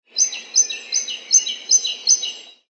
Tweet-bird-sound-effect.mp3